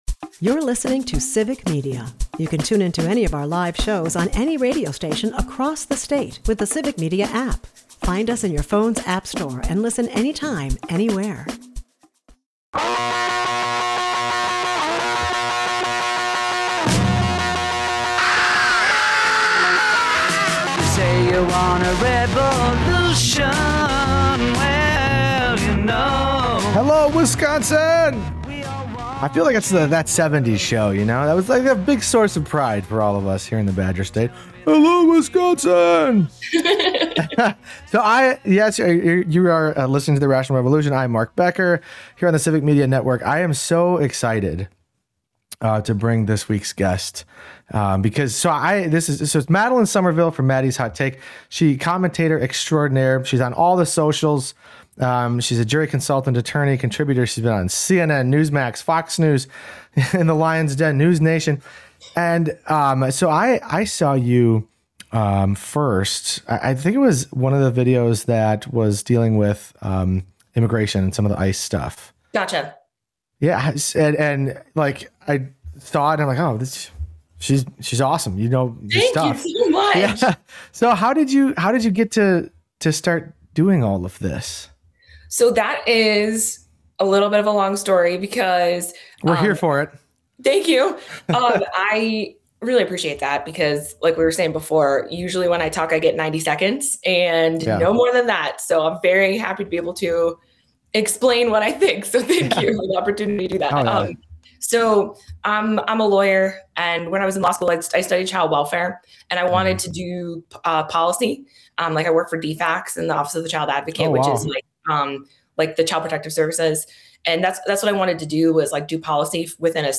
State Senator Kelda Roys joins the show to talk about her campaign for Governor. She talks about the responsibilities of the job, why experience matters, and how she will improve the lives of Wisconsinites if she's elected. They also get into a spirited discussion about the recent ICE activity and how it is unlawful, as well as how Wisconsin's backwards laws regarding women's health need to change.